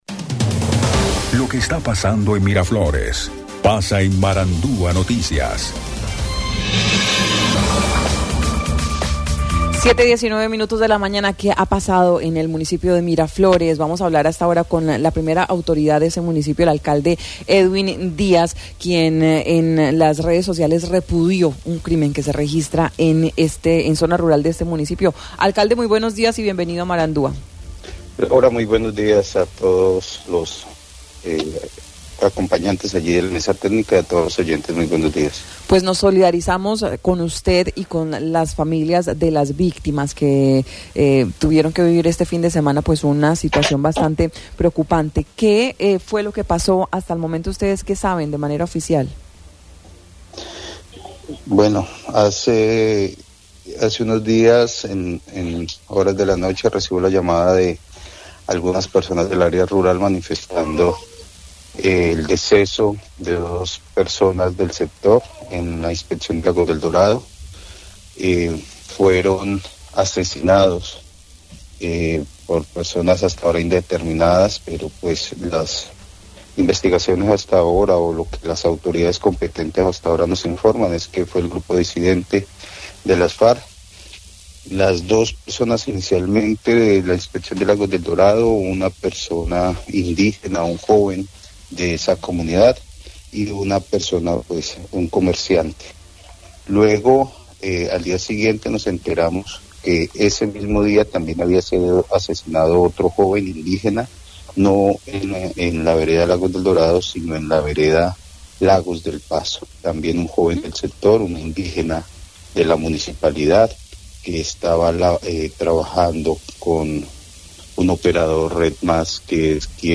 El alcalde Edwin Ioanny Díaz, se refirió al caso de los tres asesinatos registrados durante el fin de semana, donde señaló que en la Inspección de Lagos del Dorado y Lagos del Paso, por circunstancias que son motivo de investigación por parte de las autoridades se presentó el homicidio múltiple el pasado fin de semana de dos personas indígenas y un colono dedicado a la labor comercial, al parecer por integrantes de las disidencias de las Farc que operan por ese sector del Guaviare